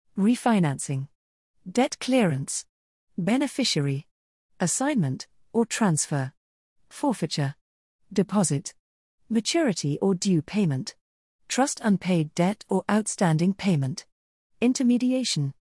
Cliquez sur les icônes fille et garçon pour écouter la prononciation.